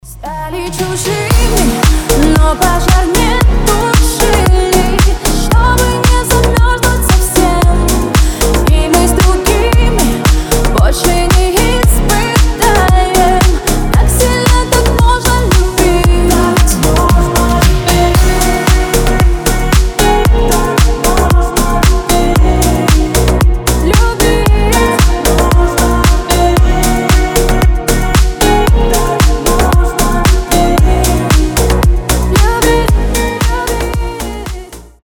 • Качество: 320, Stereo
дуэт